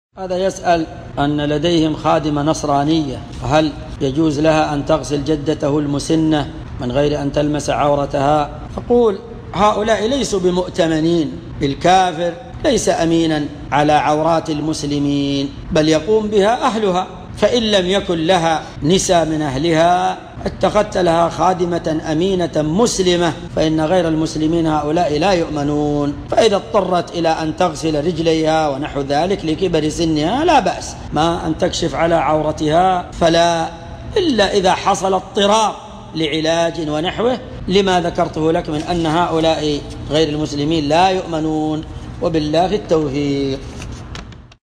مقتطف من محاضرة بعنوان : (فضل الطاعة في شهر الله المحرم) .